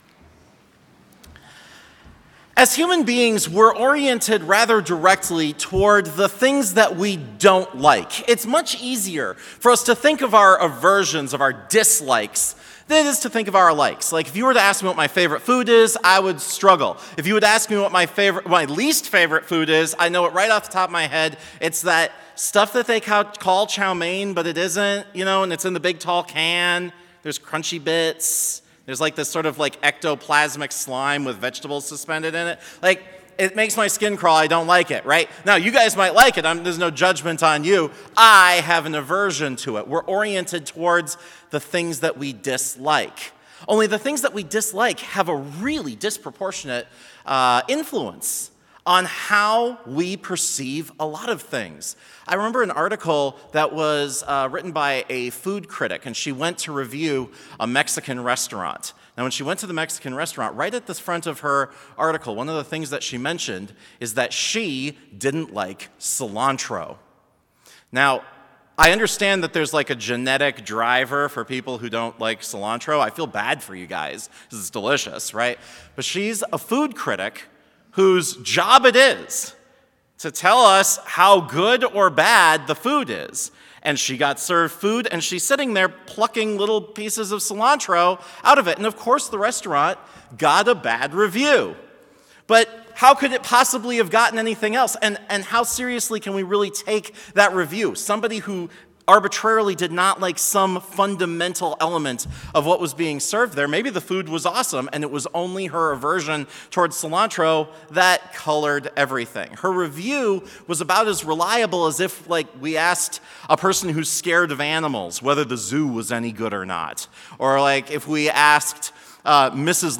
Chapel service on February 9, 2022, at Bethany Chapel in Mankato, MN, (video and audio available) reading Prelude reading Devotion reading Prayer reading Blessing reading Postlude
Complete service audio for Chapel - February 9, 2022